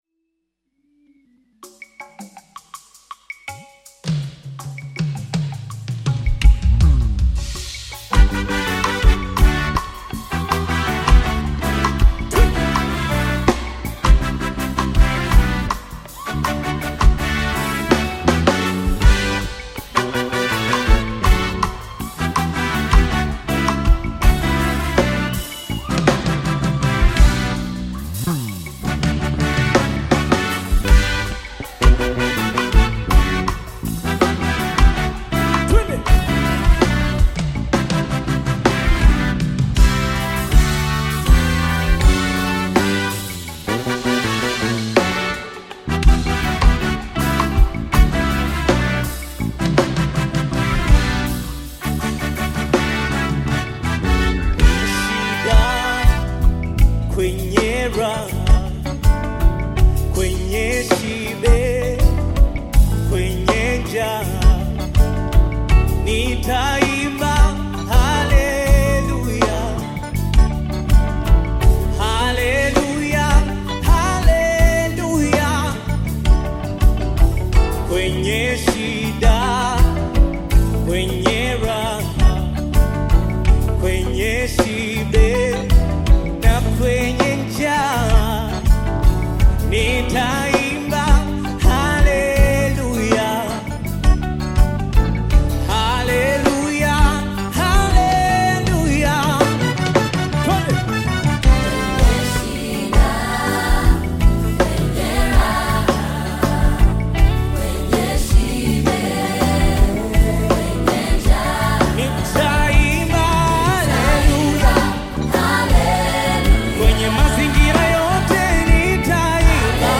African Music
Tanzanian Gospel singer and songwriter
gospel song